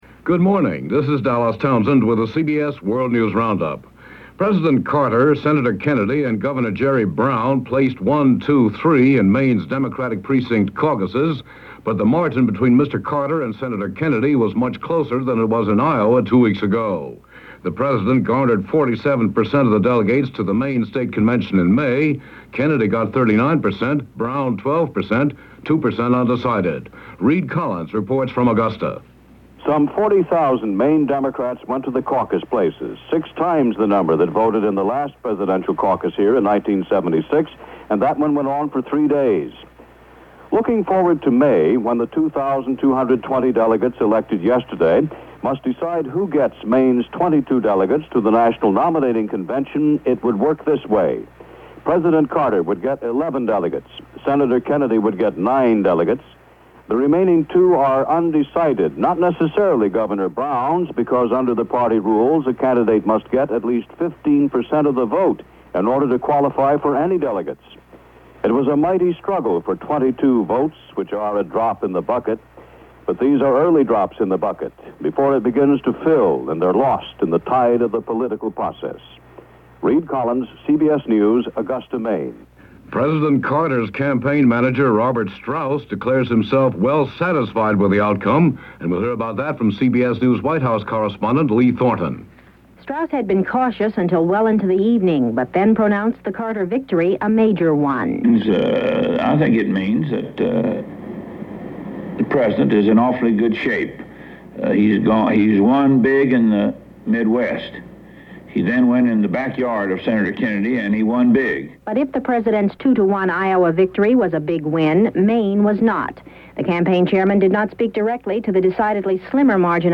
And while the caucuses were rolling along, that’s just a little of what happened, this February 11, 1980 as presented by The CBS World News Roundup.